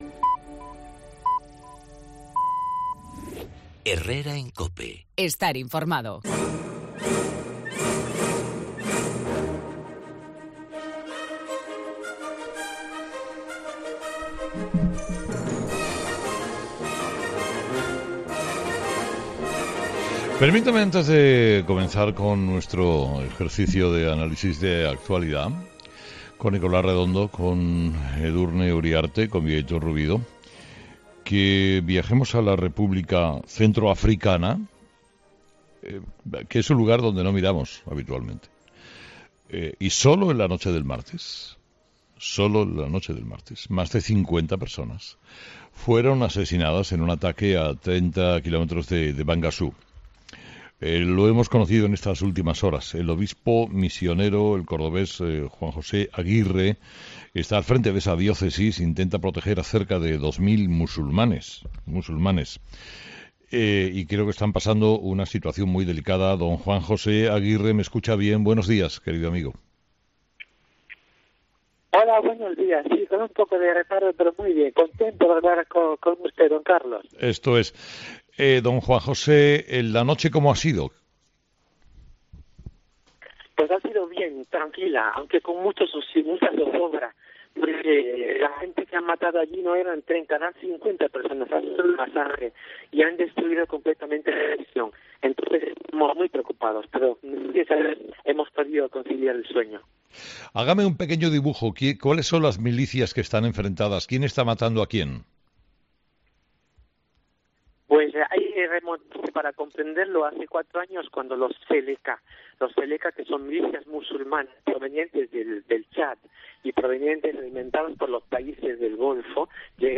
Mons. Juan José Aguirre, obispo de Bangassou, en La República Centroafricana